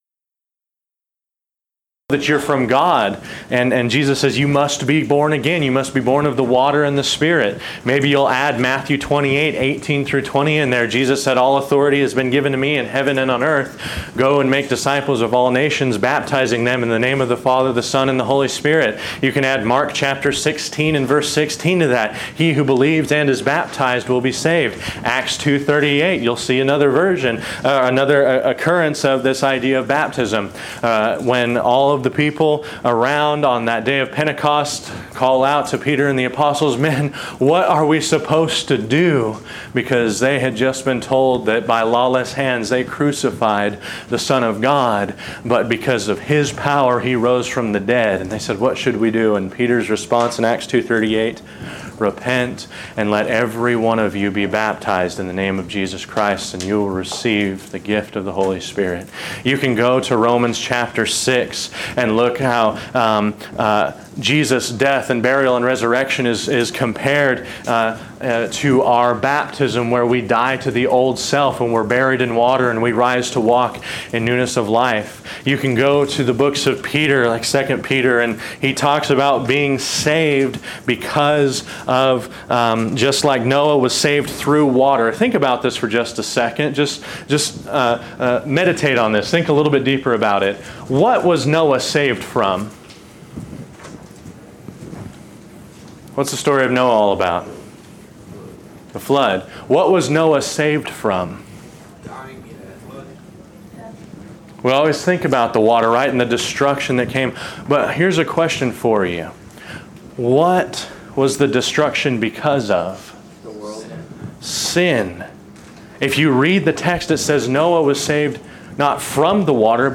Event: Discipleship U 2016
Youth Sessions